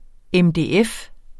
Udtale [ εmdeˈεf ]